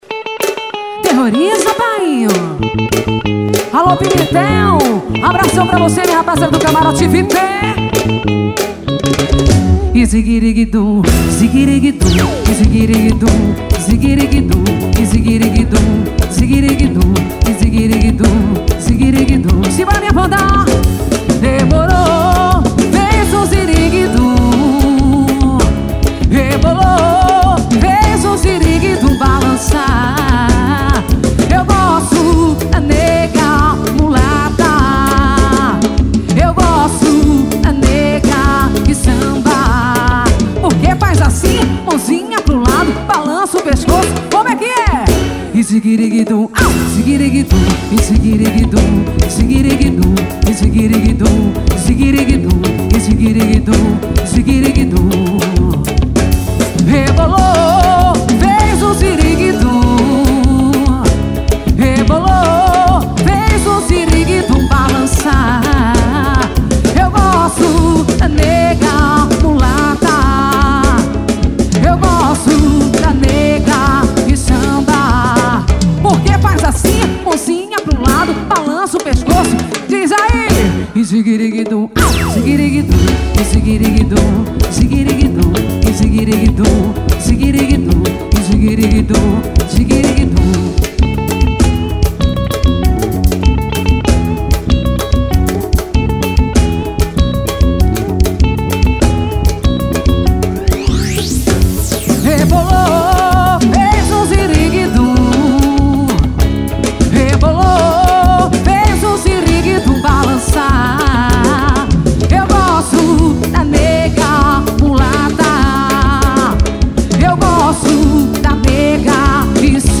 axe.